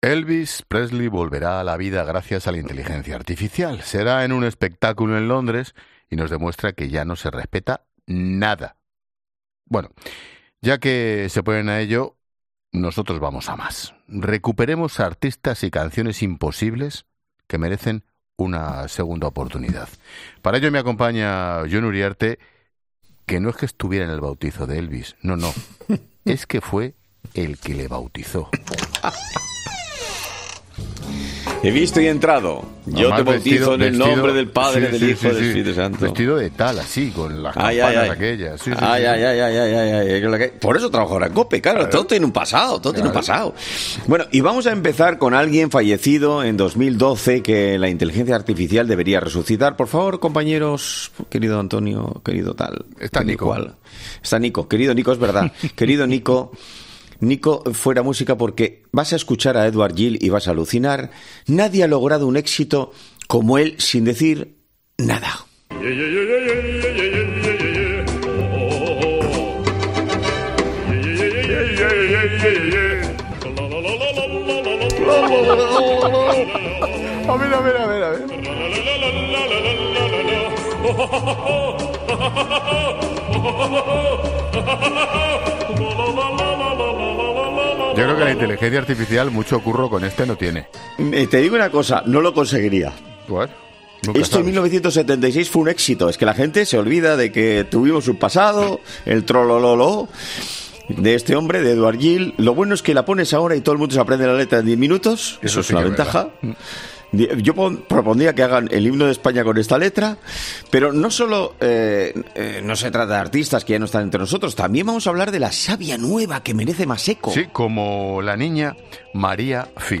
El director de La Linterna no puede contener la risa al escuchar en directo una canción dedicada a un miembro de la Familia Real: "Se lo voy a mandar, que tengo el contacto"
Ángel Expósito, al escucharlo, no puede contener las carcajadas.